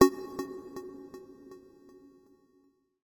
Universal UI SFX / Clicks
UIClick_Long Echo Short Attack 02.wav